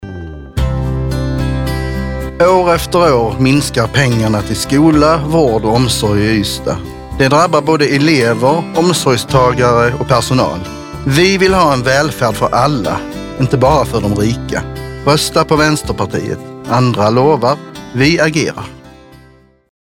Vår valrörelse sträcker sig även ut i lokalradion!